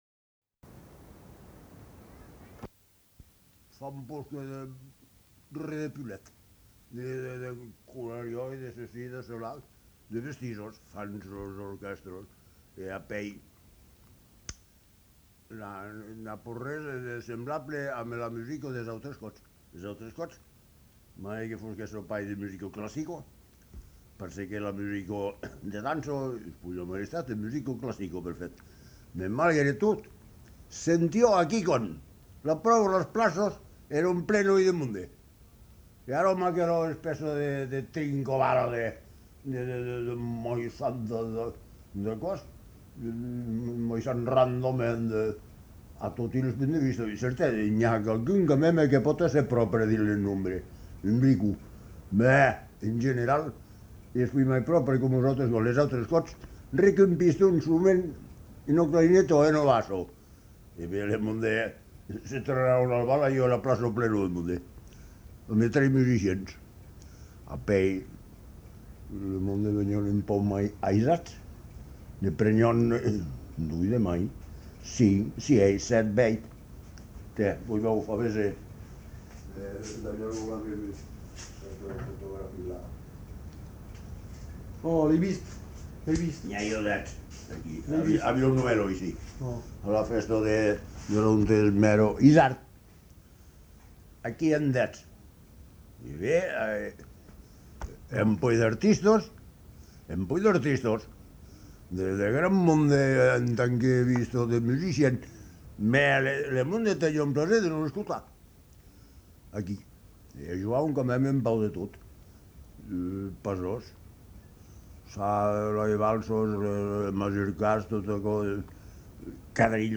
Lieu : Montgaillard-Lauragais
Genre : témoignage thématique